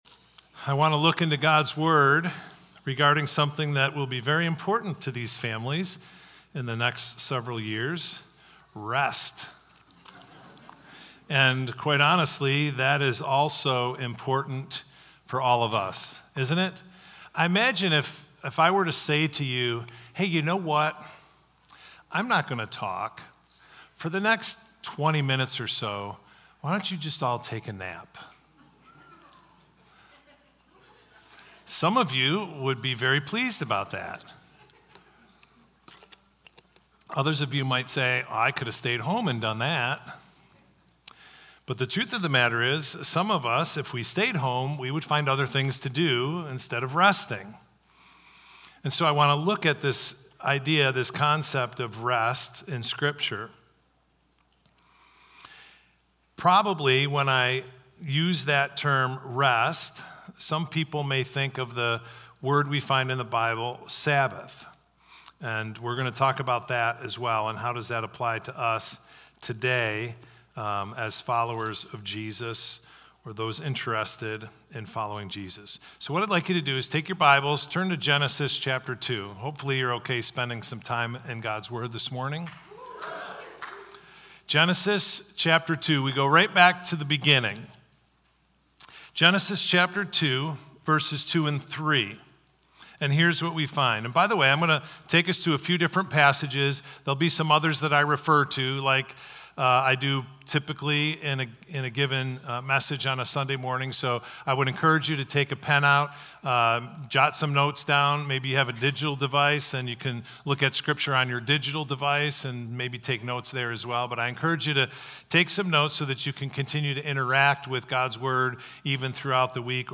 Victor Community Church Sunday Messages / What is Biblical Rest?